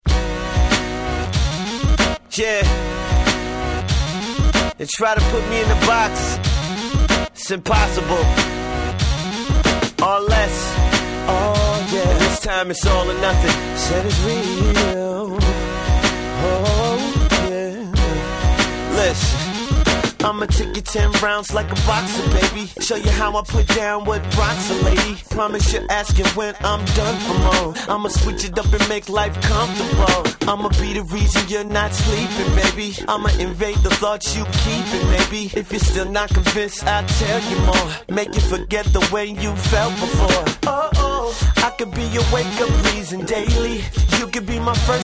08' Smash Hit R&B !!
正統派で万人受けする非常に気持ちの良い1曲です！